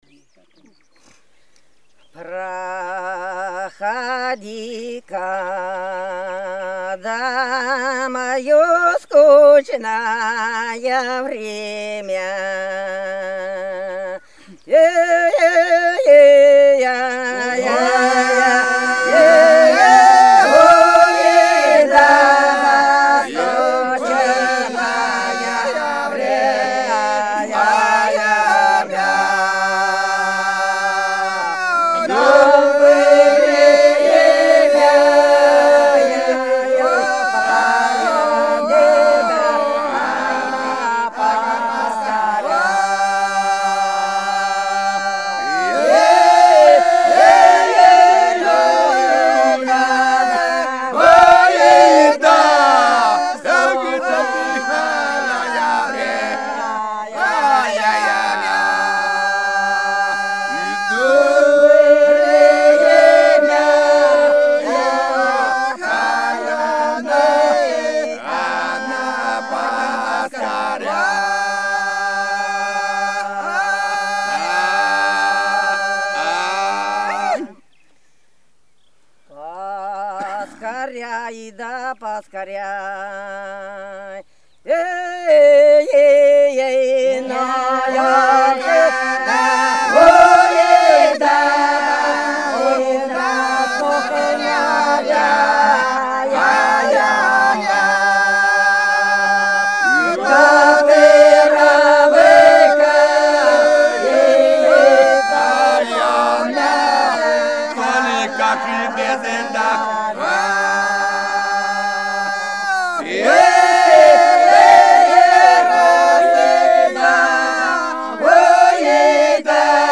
Белгородские поля (Поют народные исполнители села Прудки Красногвардейского района Белгородской области) Проходи-ка, да мое скучное время - протяжная